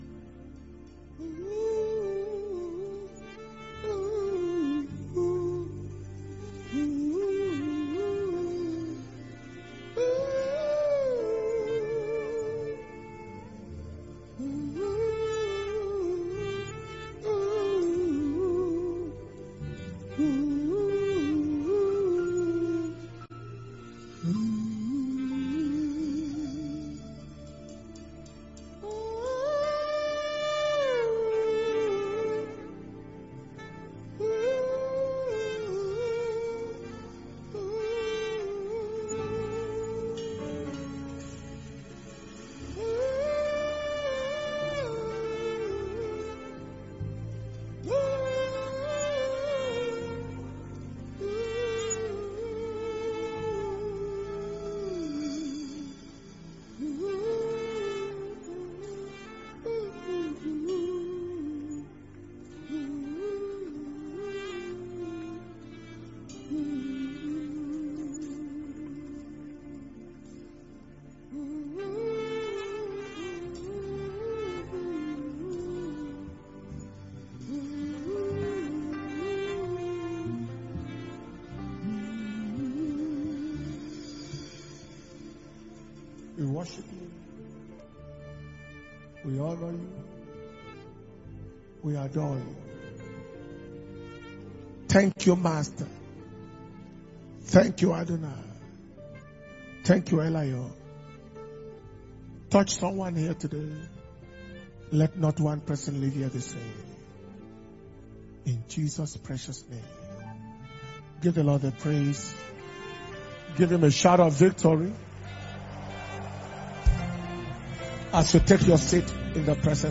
October 2021 Miracles & Prophetic Service